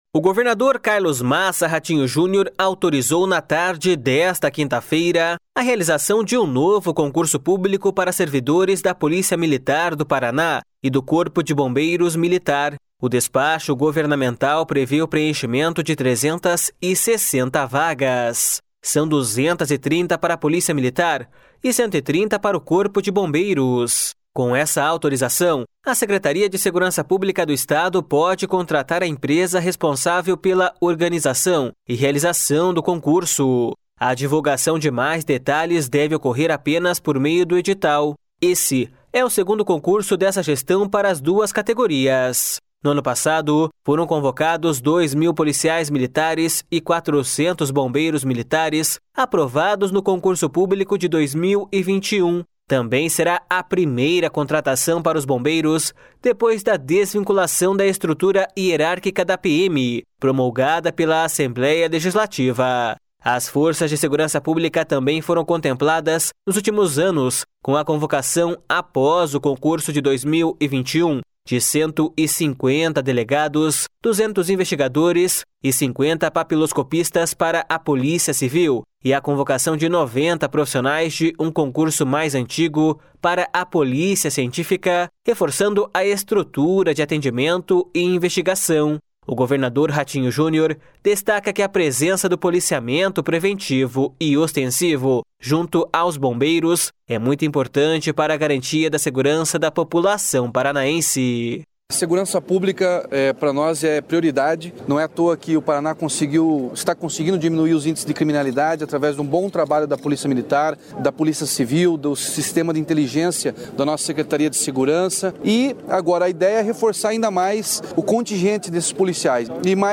O governador Ratinho Junior destaca que a presença do policiamento preventivo e ostensivo, junto aos bombeiros, é muito importante para a garantia da segurança da população paranaense.// SONORA RATINHO JUNIOR.//
O secretário de Segurança Pública, Hudson Teixeira, ressaltou que o reforço no efetivo fortalece as instituições.// SONORA HUDSON TEIXEIRA.//